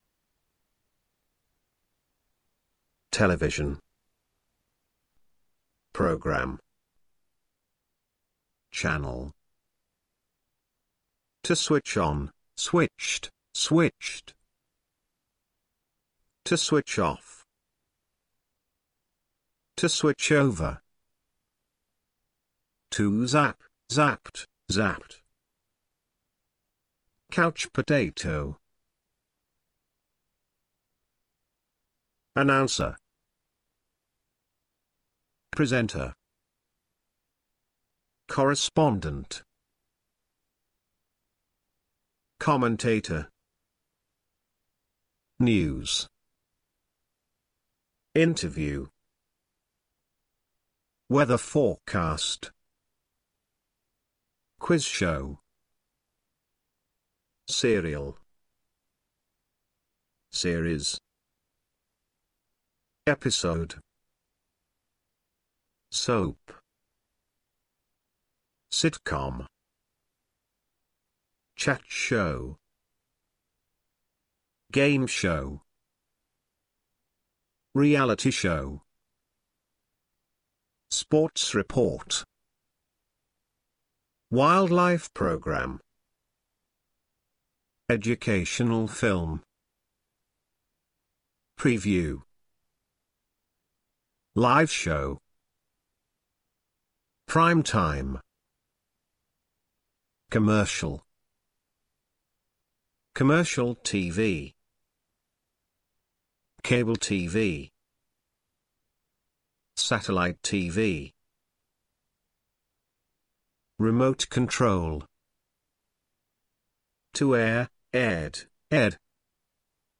Hallgasd meg a szavak kiejtését az ikonra kattintva.